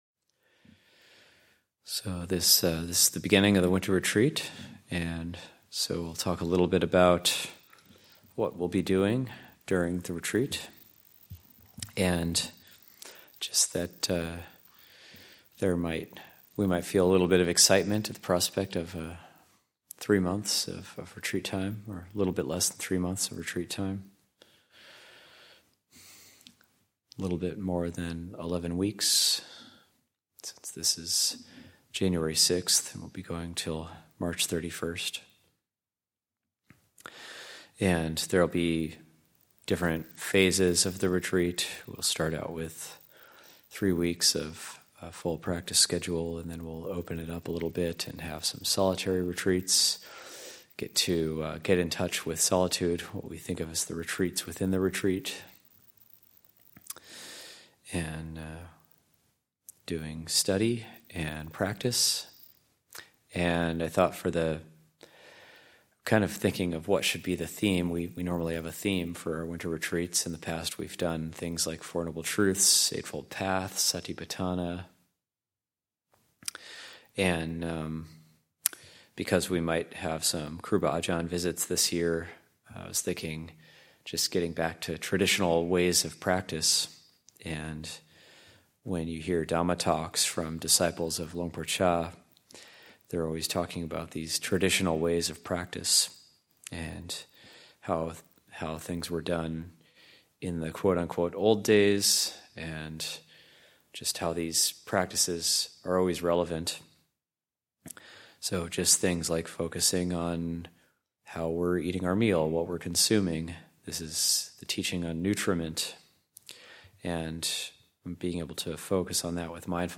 Dhamma Talks given at Abhayagiri Buddhist Monastery.